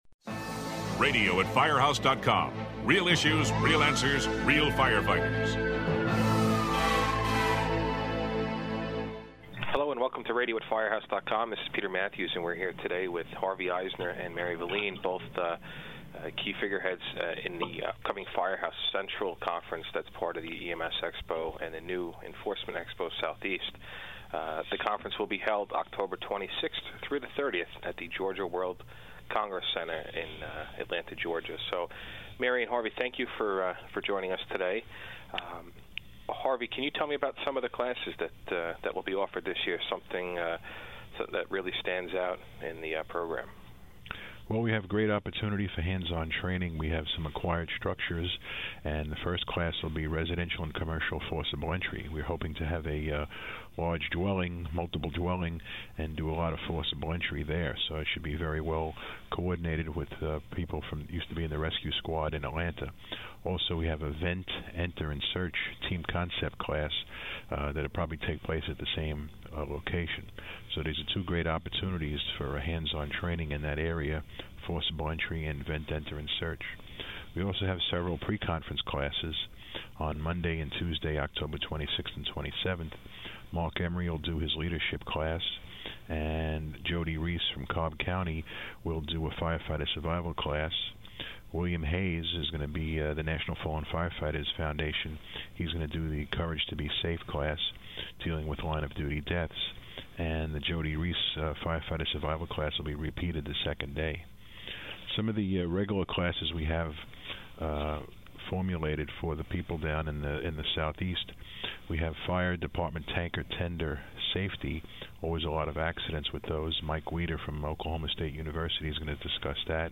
The two discuss the wide-variety of offerings at this year’s Firehouse Central, including the addition of Law Enforcement Expo Southeast.